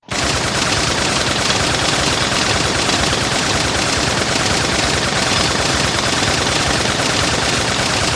minigun_shooting.wav